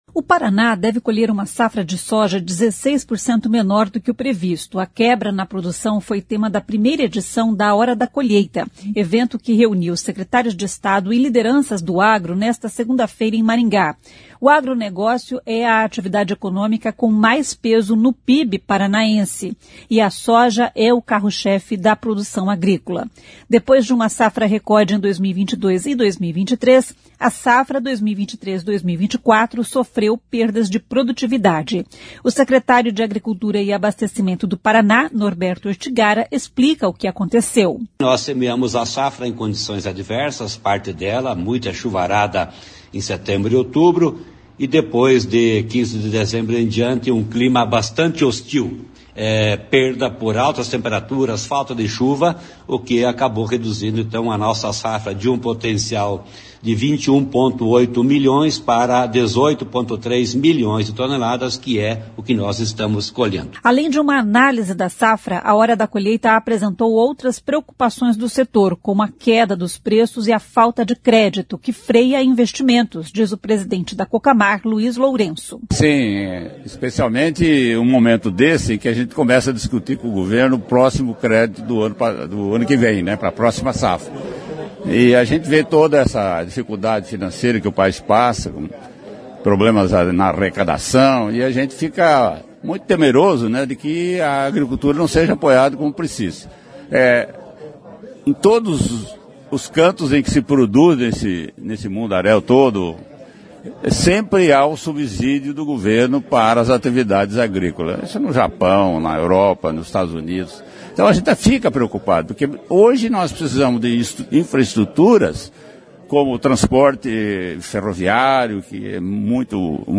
A quebra na produção foi tema da primeira edição da Hora da Colheita, evento que reuniu secretários de Estado e lideranças do agro nesta segunda-feira (29) em Maringá.
O secretário de Agricultura e Abastecimento do Paraná, Norberto Ortigara, explica o que aconteceu.
Outra boa notícia para o setor é a regulamentação do autocontrole sanitário que irá expandir a capacidade de produção de proteína animal no país, reforçou o secretário de Estado da Indústria, Comércio e Serviços, Ricardo Barros.
A Hora da Colheita é um evento da CBN Maringá e foi realizado na Sicredi Dexis.